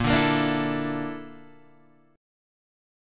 BbM#11 chord